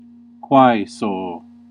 Ääntäminen
IPA: /tak/